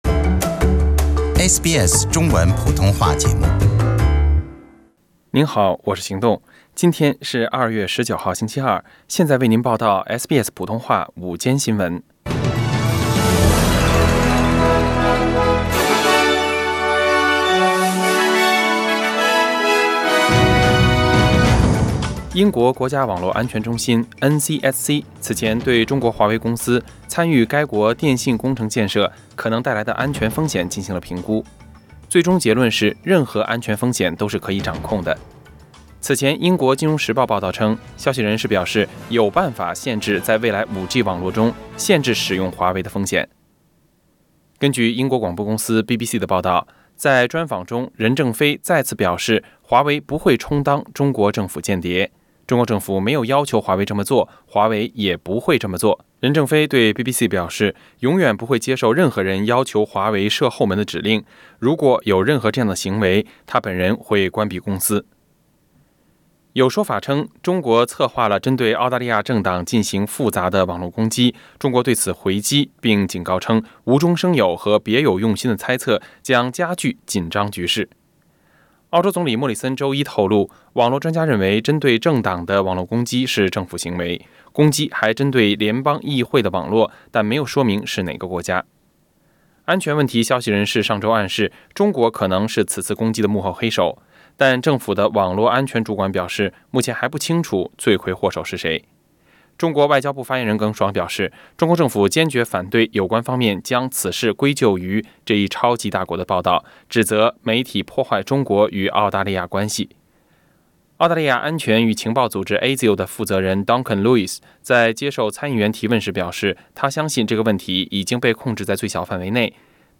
SBS Chinese News Source: SBS Mandarin